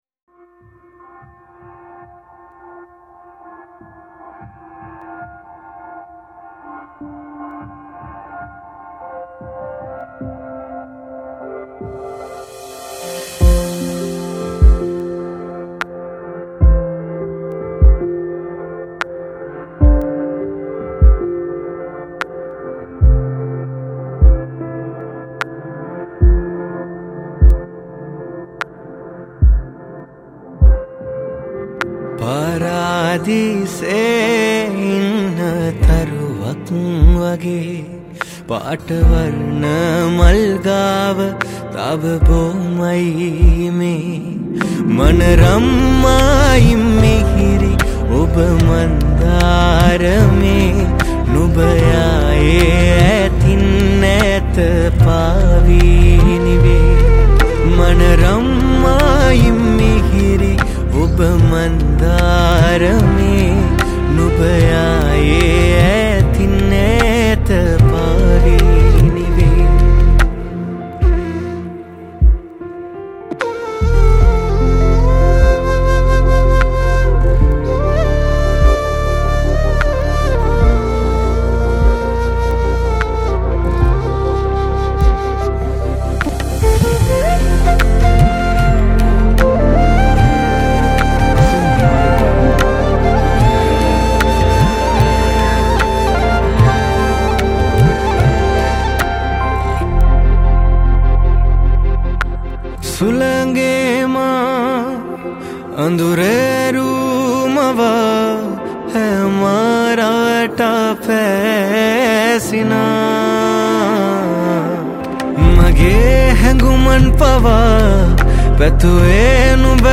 Vocals
Guitars
Flutes